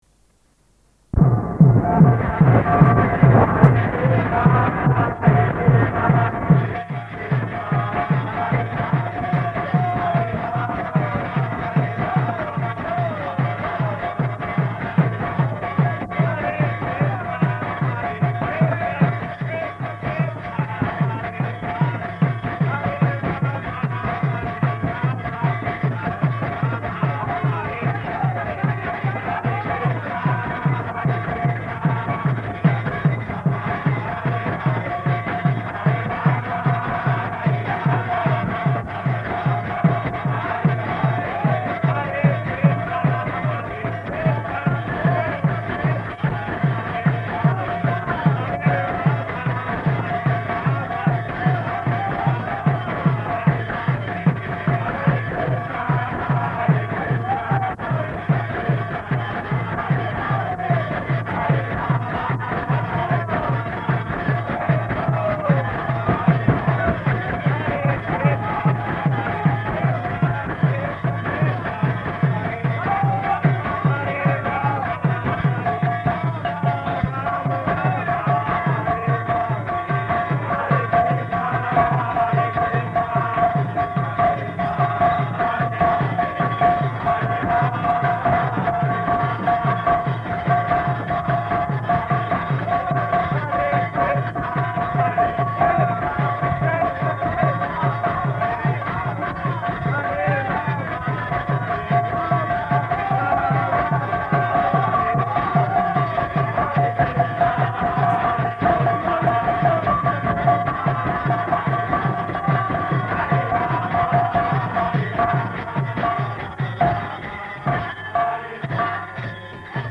Lecture
Lecture --:-- --:-- Type: Lectures and Addresses Dated: April 20th 1972 Location: Tokyo Audio file: 720420LE.TOK.mp3 [ kīrtana ] Prabhupāda: [ prema-dhvanī ] Thank you very much.